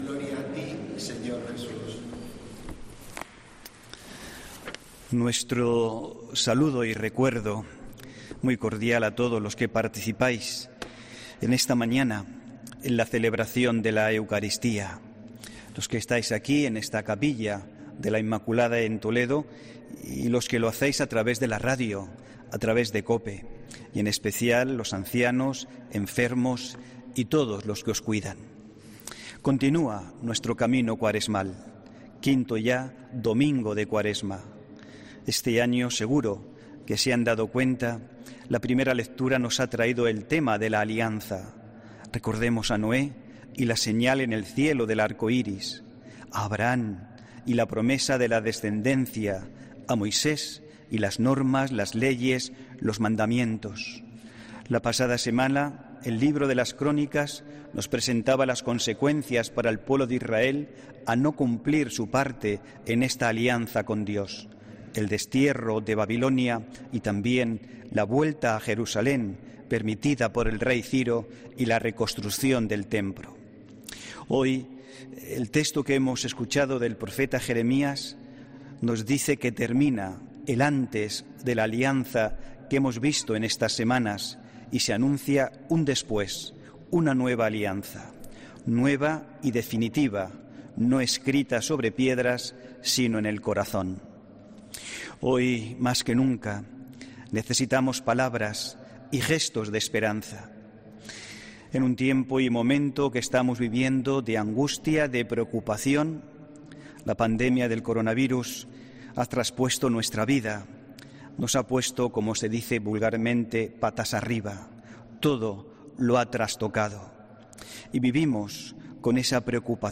HOMILÍA 21 MARZO 2021